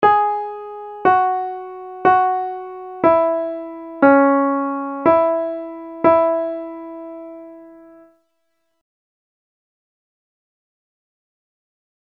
Key written in: E Major
Comments: Nice gentle reassuring tag.
Each recording below is single part only.
Other part 1: